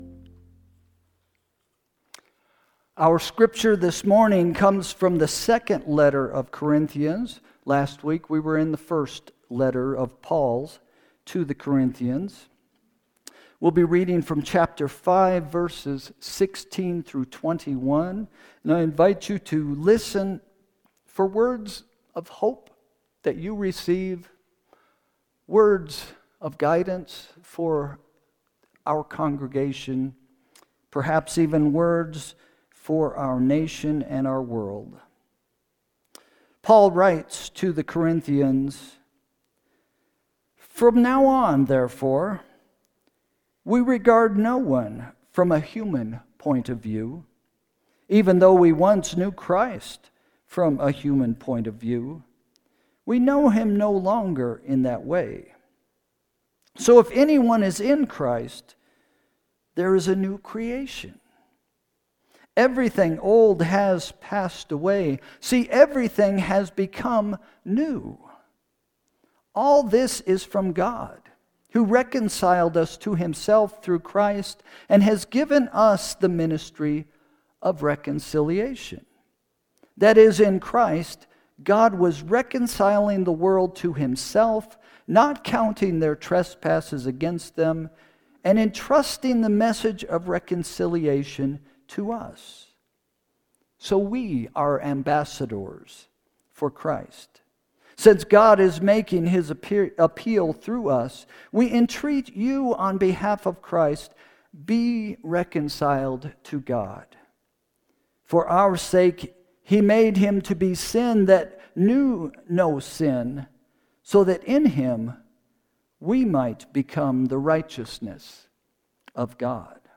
Sermon – June 29, 2025 – “Ancient Hope” – First Christian Church